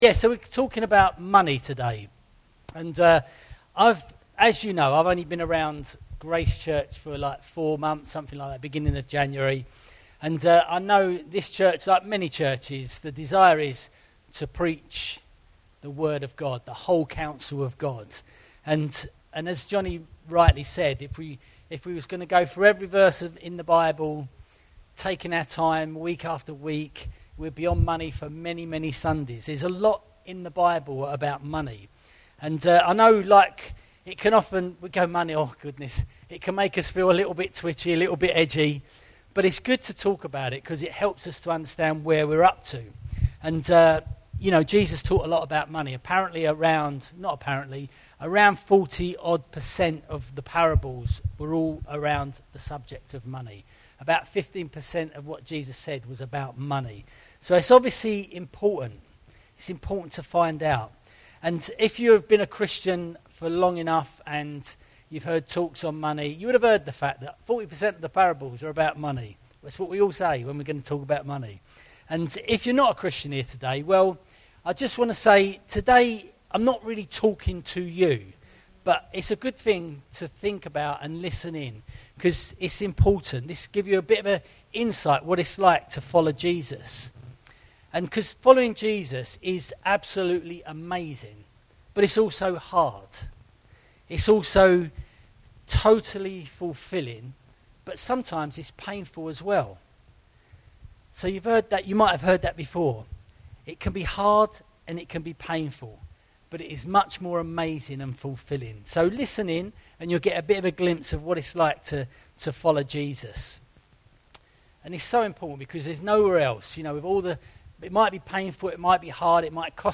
Series: Miscellaneous Sermons 2022